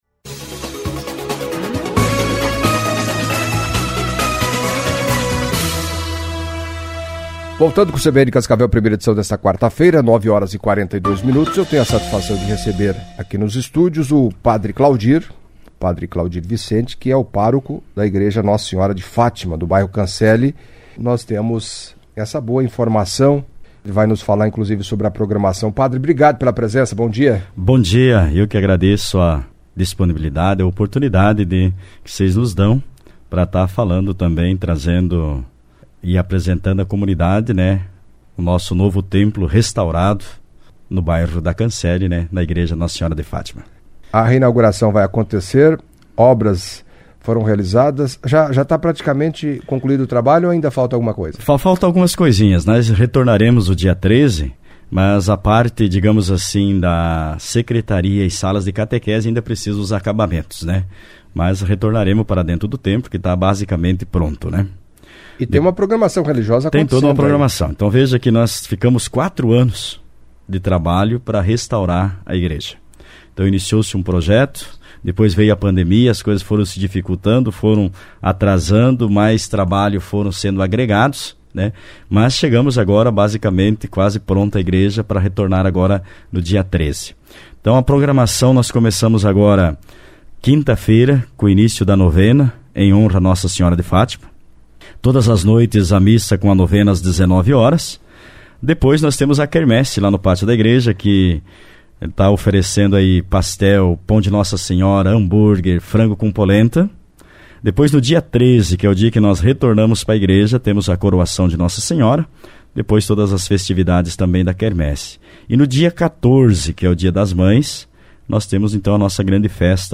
Em entrevista à CBN Cascavel nesta quarta-feira (03),